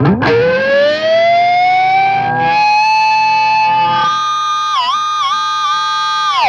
DIVEBOMB22-L.wav